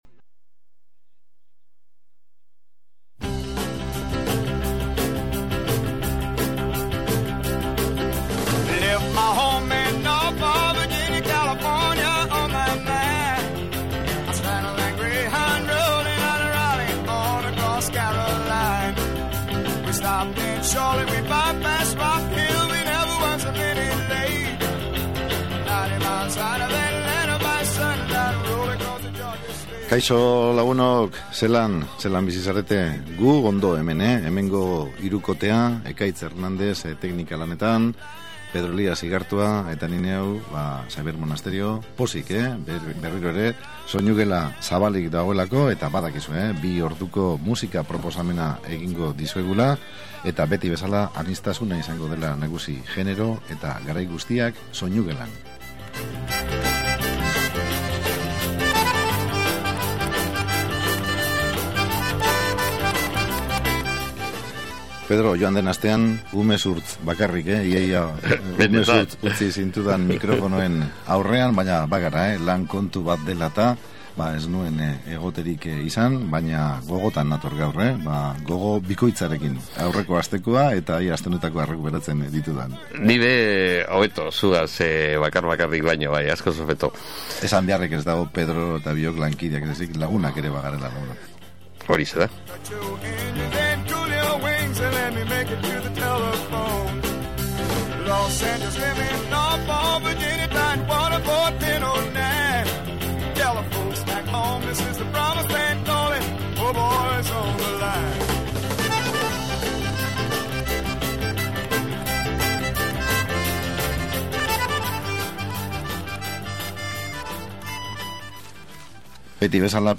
Kantu anitz, musikari anitz eta musika anizkuna
reggae giroan
bi emakumeren ahots paregabeez